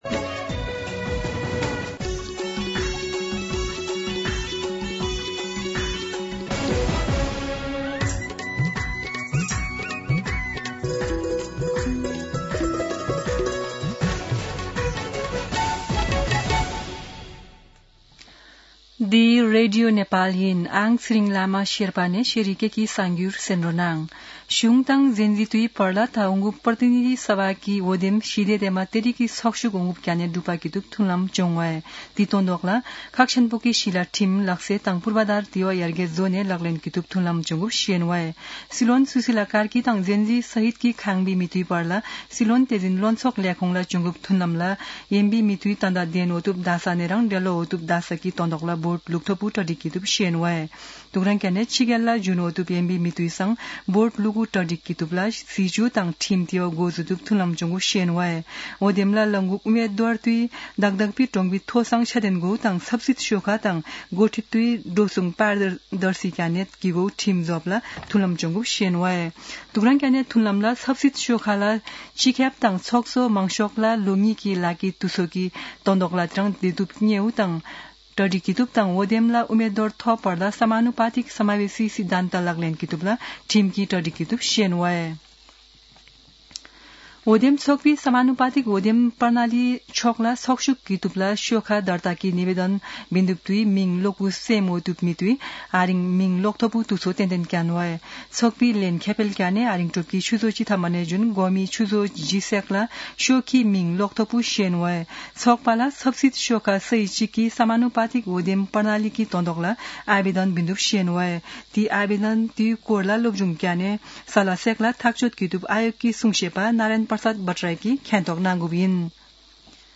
शेर्पा भाषाको समाचार : २५ मंसिर , २०८२
Sherpa-News-8-25.mp3